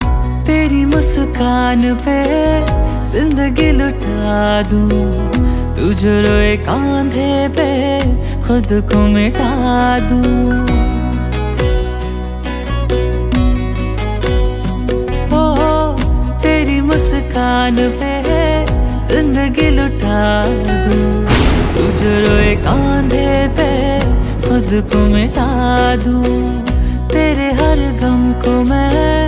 Hindi Songs
• Simple and Lofi sound
• Crisp and clear sound